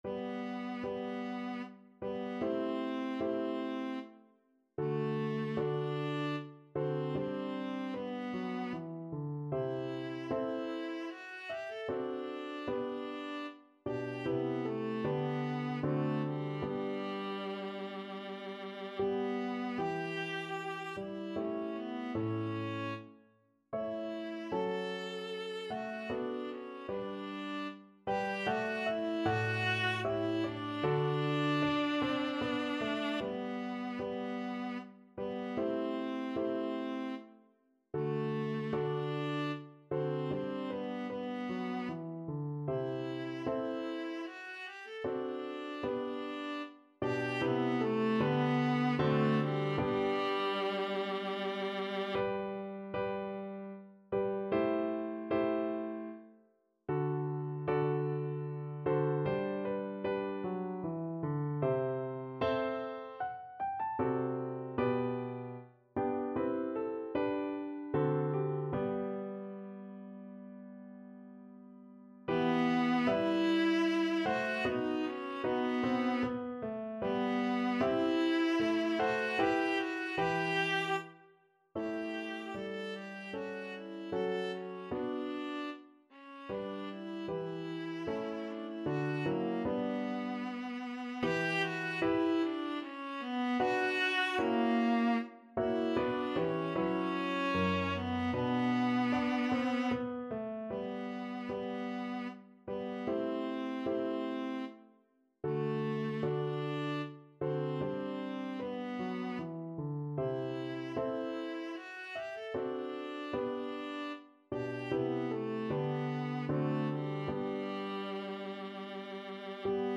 Free Sheet music for Viola
Viola
3/4 (View more 3/4 Music)
G major (Sounding Pitch) (View more G major Music for Viola )
Larghetto = 76
Classical (View more Classical Viola Music)